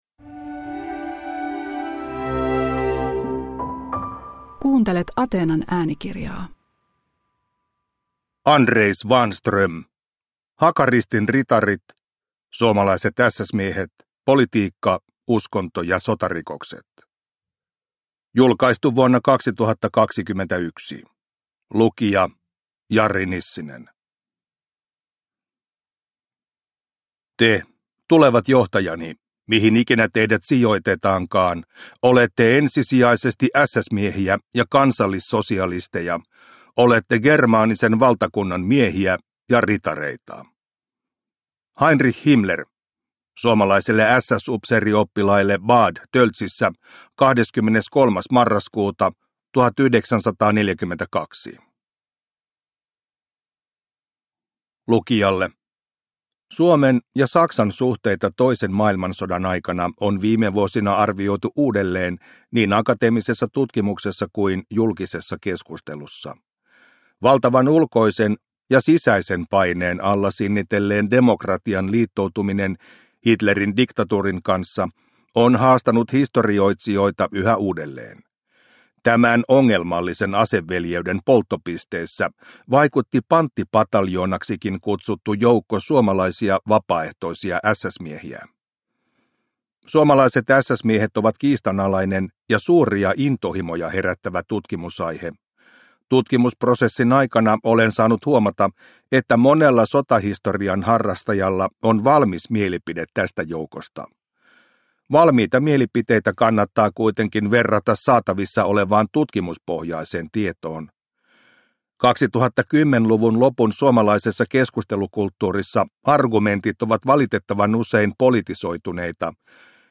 Hakaristin ritarit – Ljudbok – Laddas ner